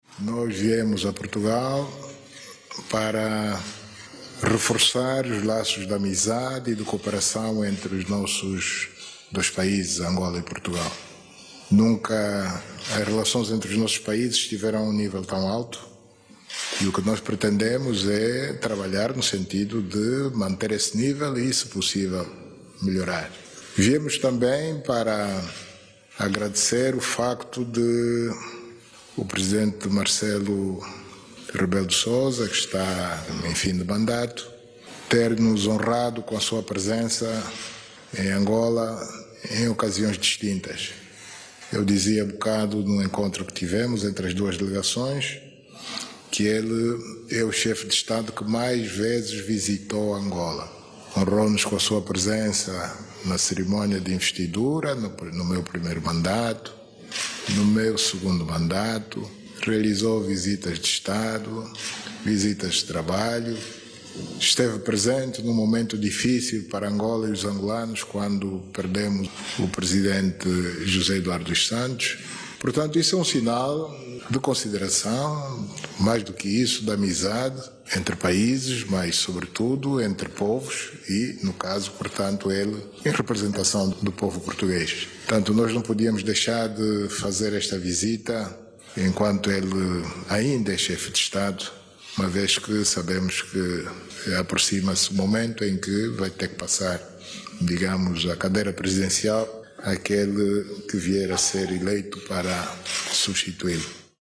NOTÍCIAS
O estadista angolano fez estas declarações no Palácio de Belém, onde foi recebido pelo Presidente de Portugal, Marcelo Rebelo de Sousa.